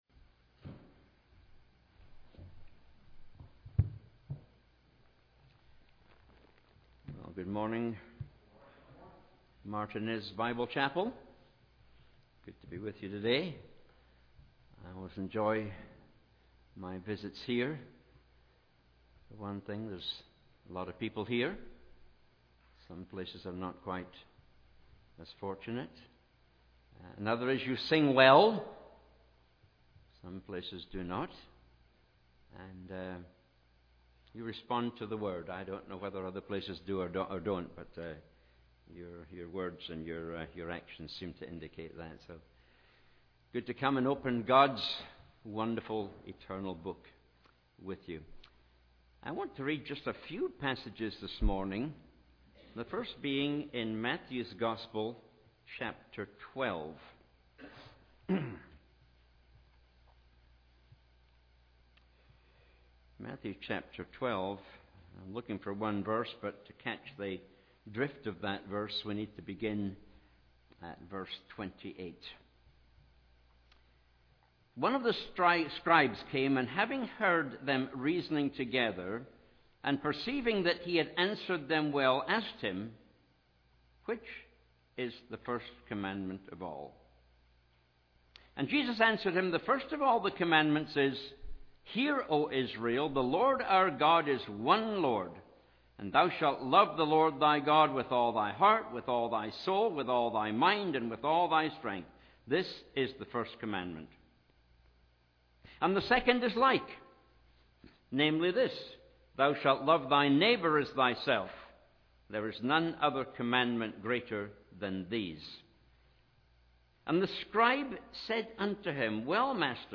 Weekly Sermons - Martinez Bible Chapel - Page 28
Service Type: Family Bible Hour